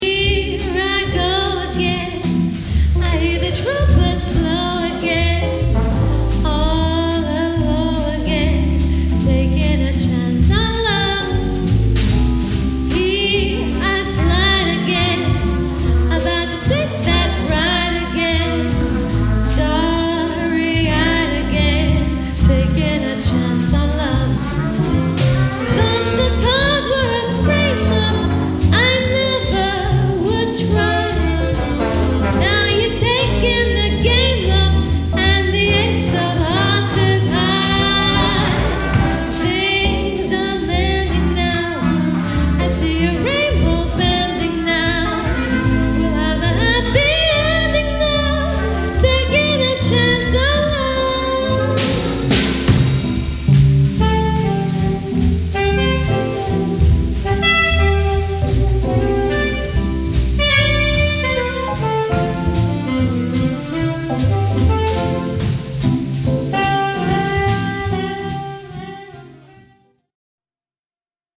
Jazz Bands
Duo jazz to Big Bands